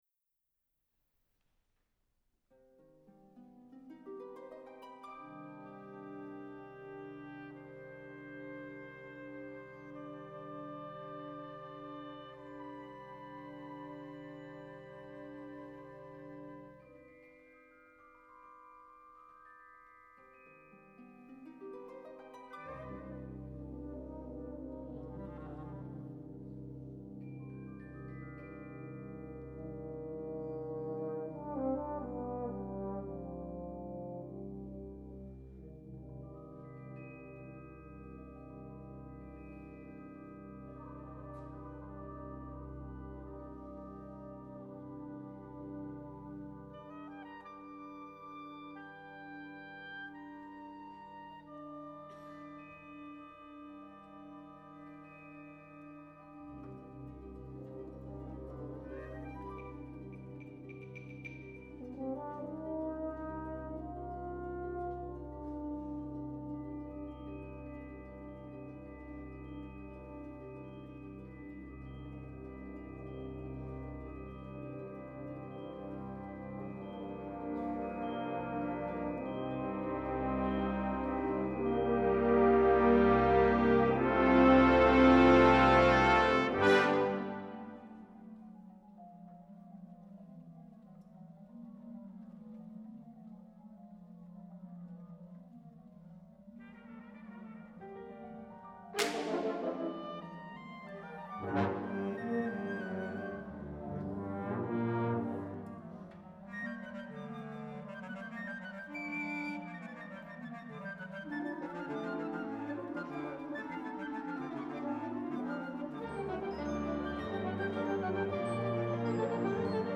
Genre: Band
Harp
Percussion 4 (5-octave marimba, chimes, 3 brake drums)
This motive is heard throughout the piece.